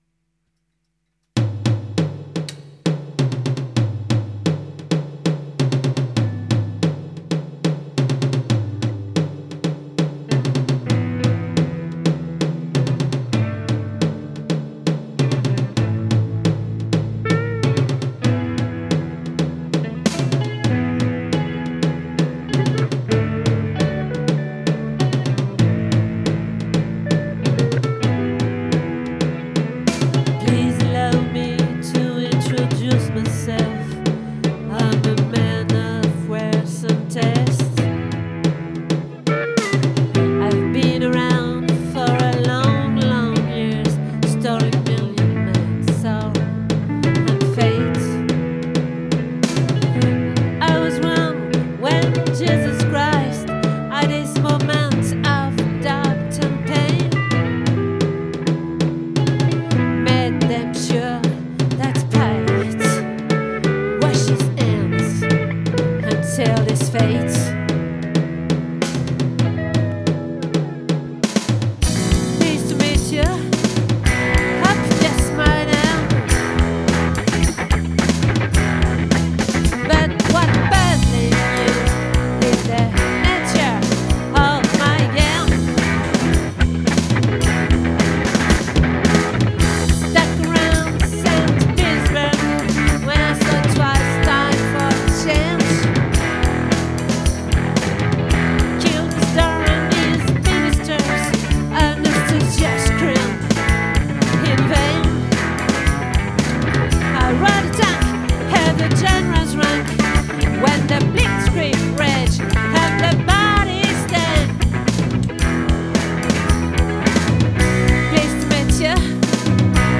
🏠 Accueil Repetitions Records_2024_12_09